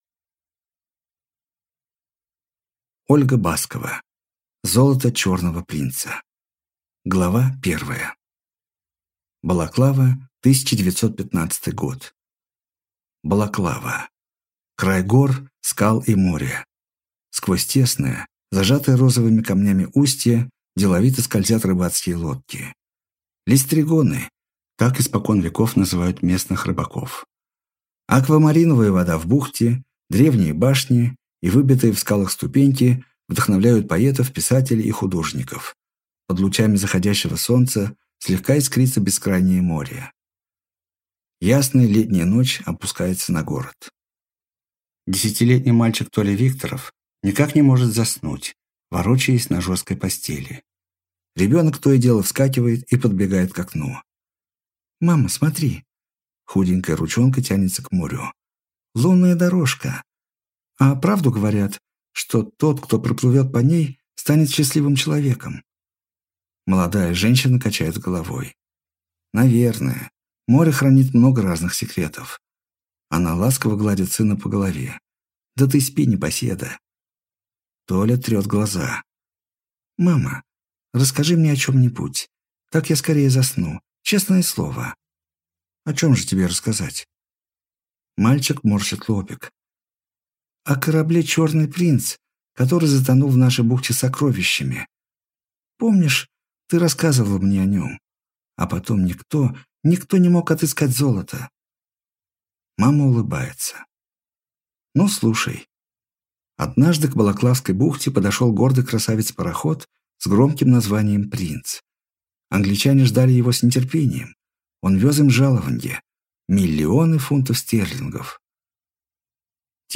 Аудиокнига Золото «Черного принца» | Библиотека аудиокниг